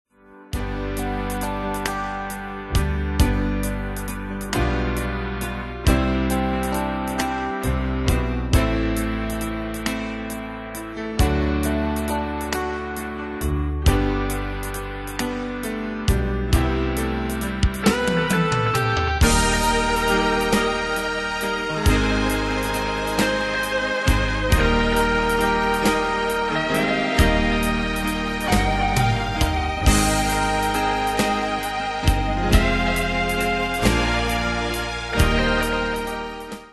Demos Midi Audio
Style: PopFranco Année/Year: 2005 Tempo: 90 Durée/Time: 3.47
Danse/Dance: Ballade Cat Id.